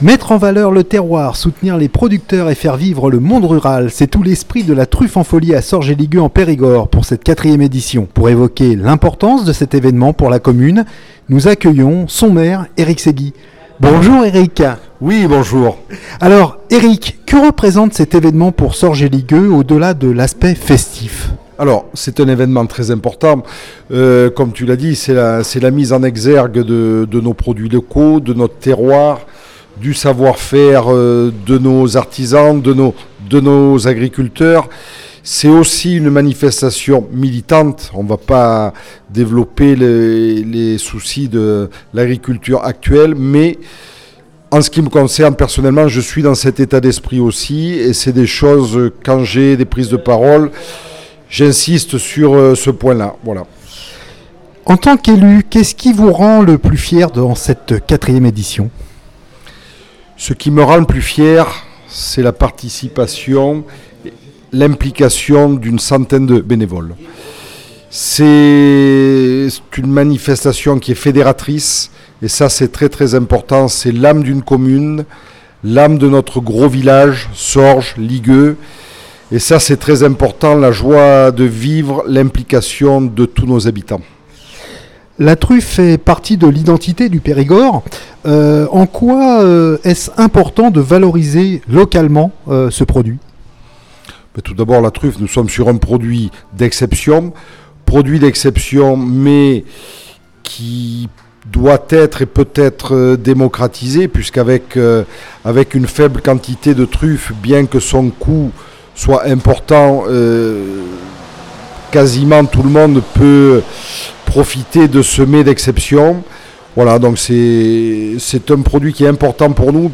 Pour évoquer l’importance de " Truffes en Folie " pour la commune, nous accueillons aujourd’hui son Maire Eric Seguy en direct de Truffes en Folie 2026.